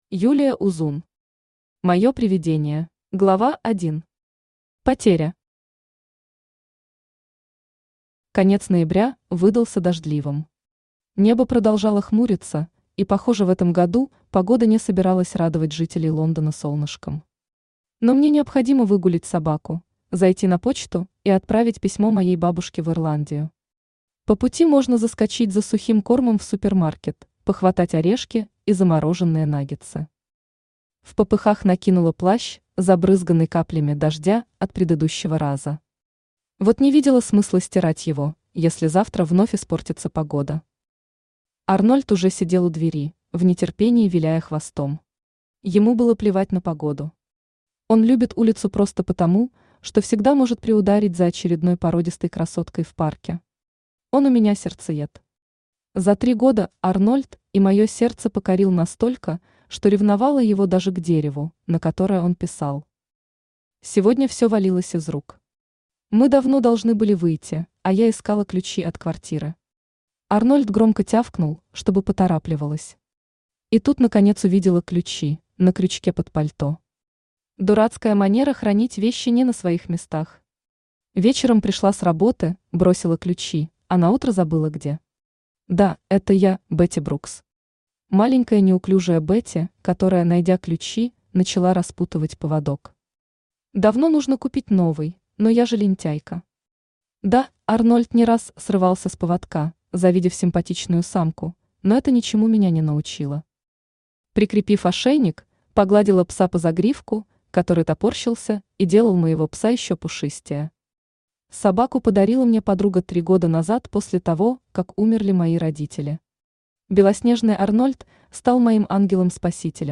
Аудиокнига Моё привидение | Библиотека аудиокниг
Aудиокнига Моё привидение Автор Юлия Узун Читает аудиокнигу Авточтец ЛитРес.